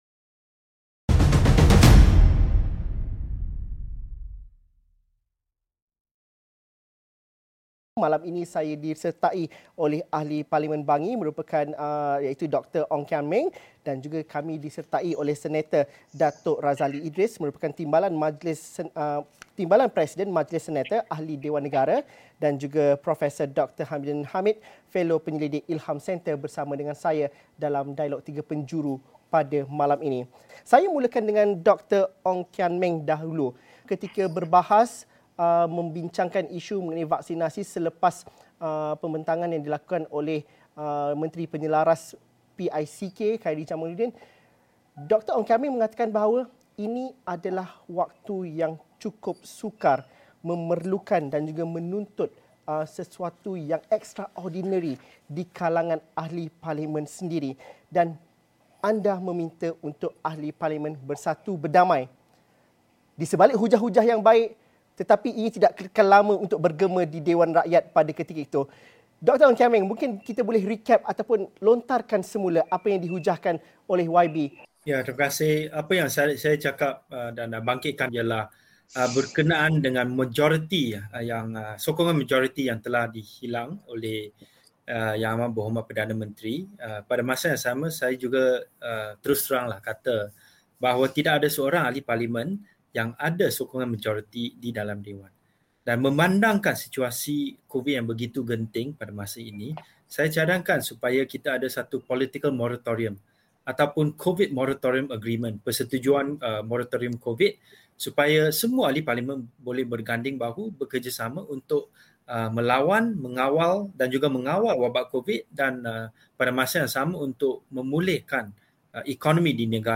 Mampukah semua Ahli Parlimen menolak semangat kepartian dan bekerjasama membantu menyelesaikan masalah COVID-19? Apa peranan pembangkang dalam usaha membantu kerajaan ke arah pemulihan negara? Diskusi dalam Dialog Tiga Penjuru 8.30 malam.